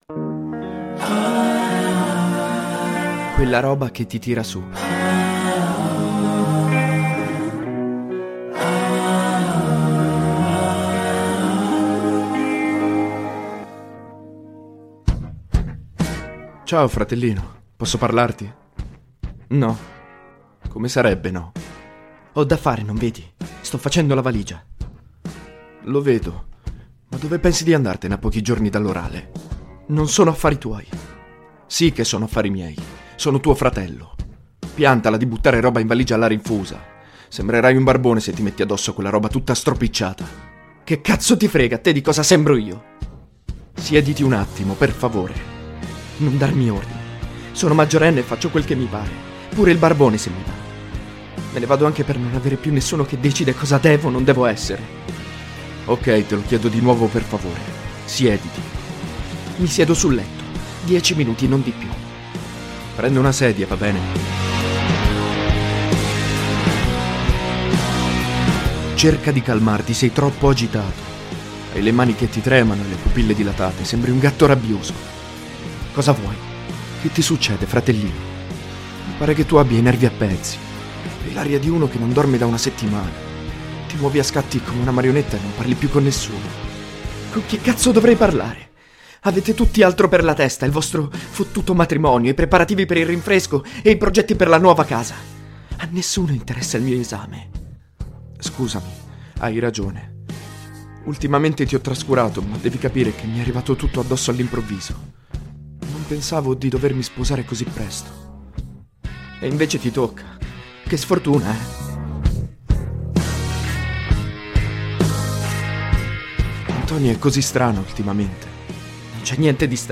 La colonna sonora è costituita da versioni strumentali o cover di "The Sky is a Neighborhood" dei Foo Fighters.
The soundtrack consists of instrumental or cover versions of "The Sky is a Neighborhood" by Foo Fighters.